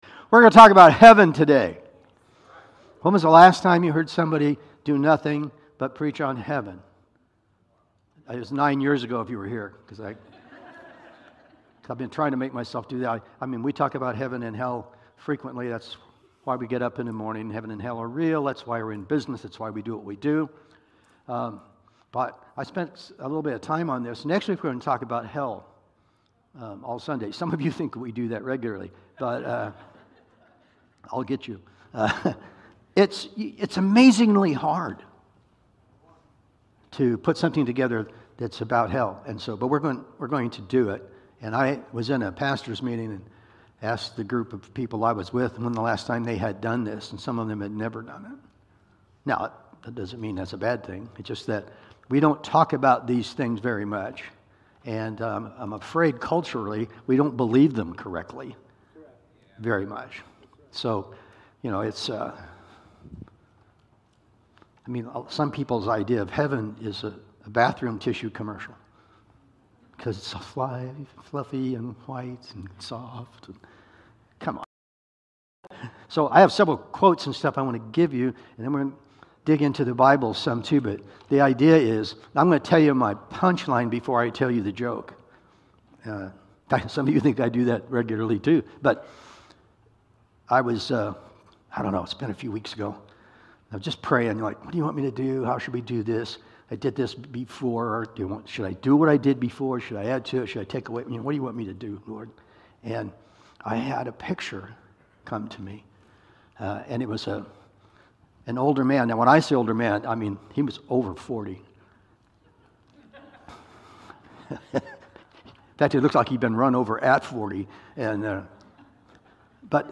Sunday Morning Sermon